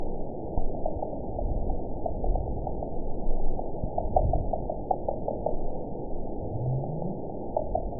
event 922378 date 12/30/24 time 05:05:44 GMT (5 months, 2 weeks ago) score 9.67 location TSS-AB03 detected by nrw target species NRW annotations +NRW Spectrogram: Frequency (kHz) vs. Time (s) audio not available .wav